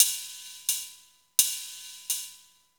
Closed Hats
HIHAT_KING_SEAT.wav